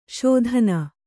♪ śodhana